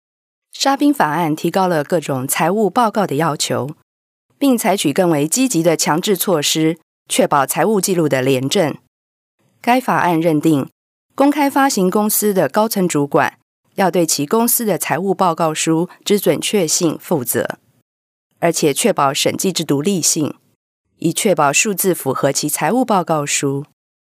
Sprecherin taiwanesisch für Werbung, TV, Radio, Industriefilme und Podcasts.
Sprechprobe: eLearning (Muttersprache):
Professional female voice over artist taiwanese.